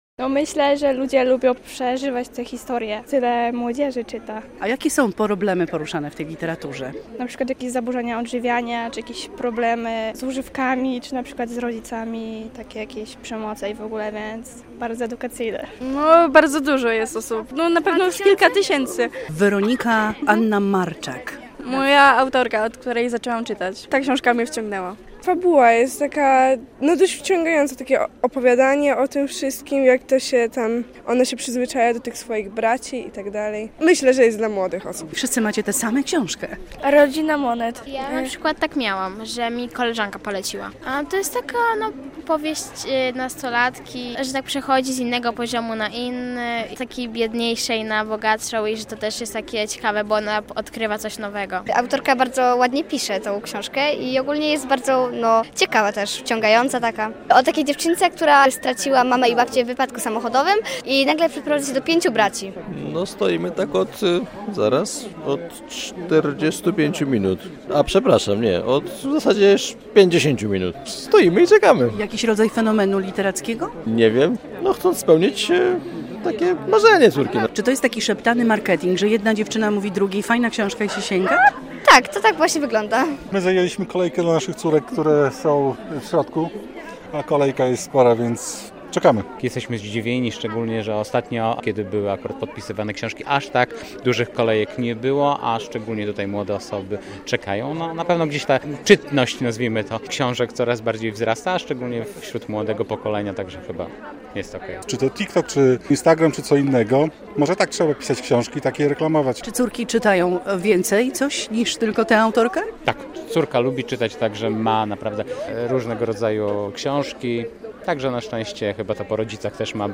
na Targach Książki w Białymstoku - relacja